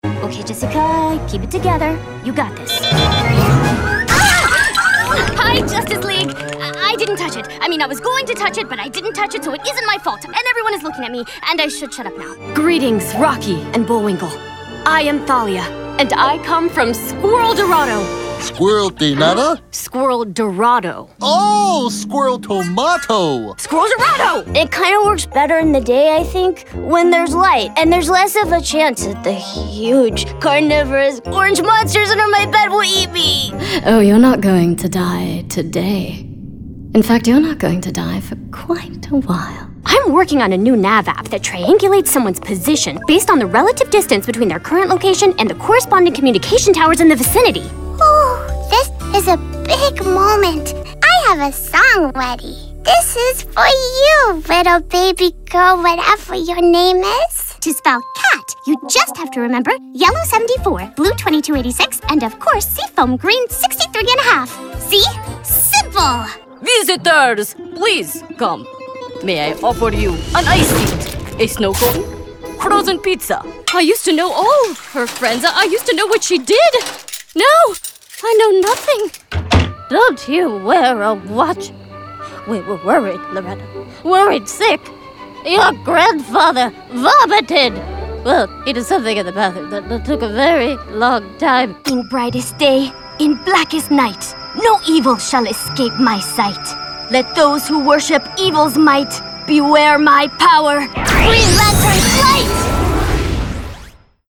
All of our contracted Talent have broadcast quality home recording studios.
Animation Demo Video Demos Animation Reel Back to Voiceover Talents